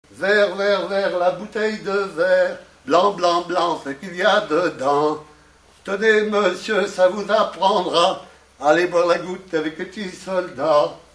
enfantine : pour autres jeux
Pièce musicale inédite